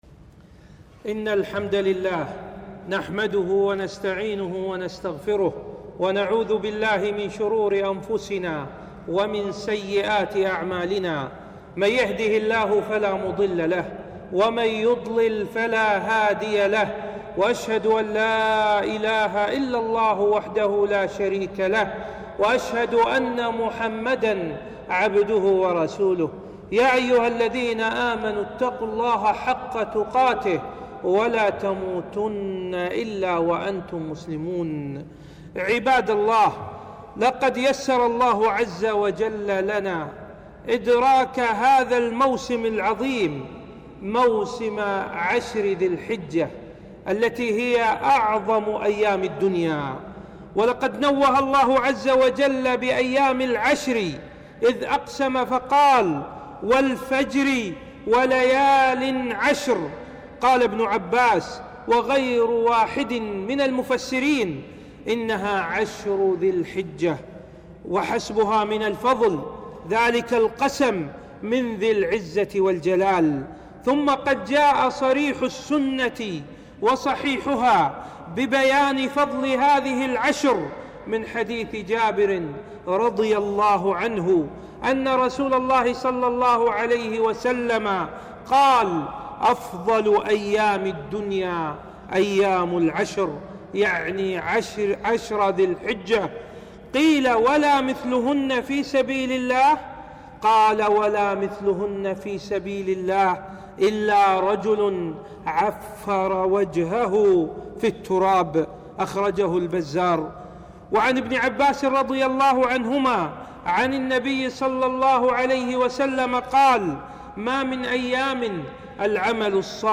خطبة - فضل وأعمال العشر من ذي الحجة